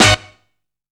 SCRAPING.wav